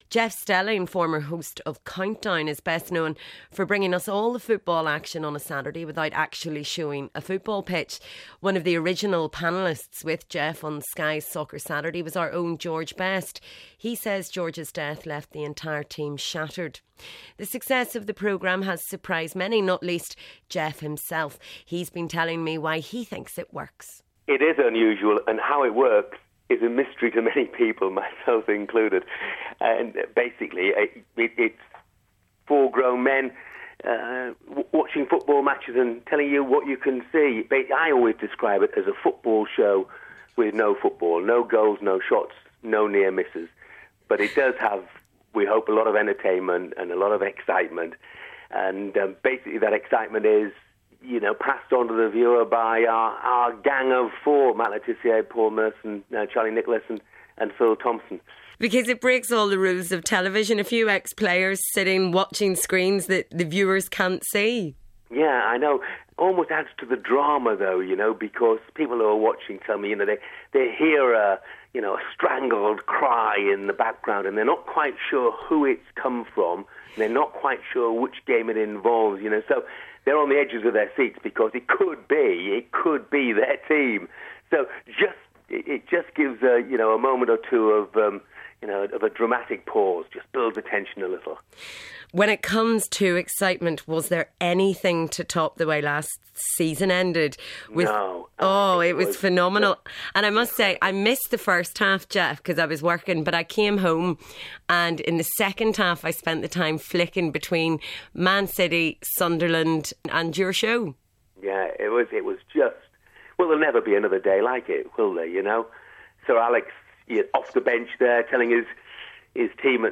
This week's Big Friday Interview is Soccer Saturday presenter Jeff Stelling